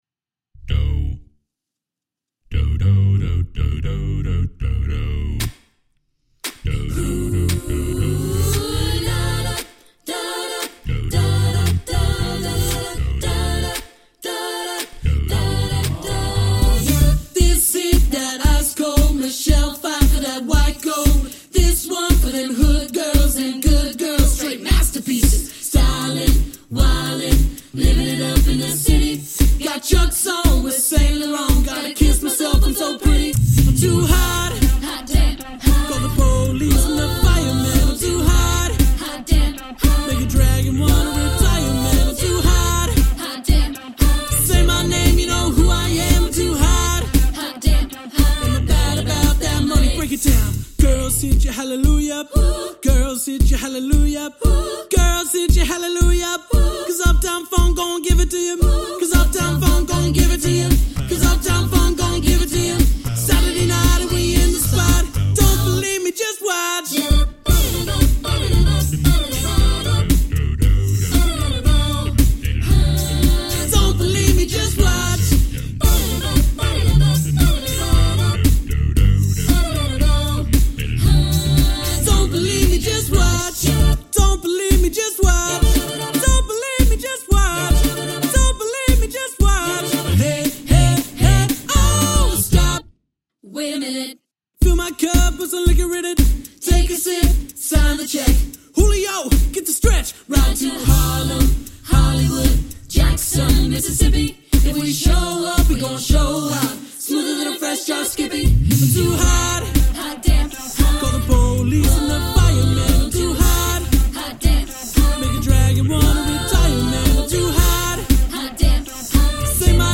Voicing: SATB divisi a cappella